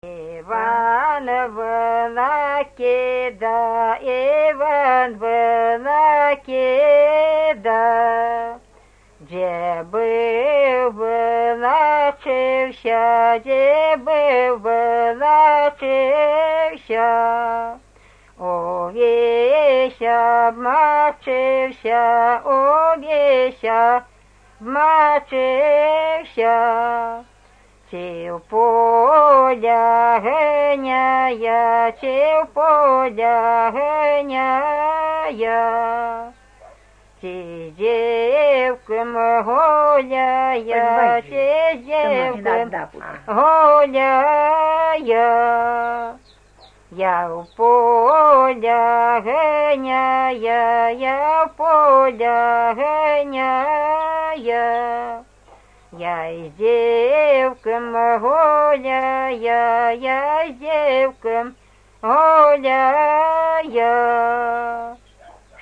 Купальные обрядовые песни Невельского района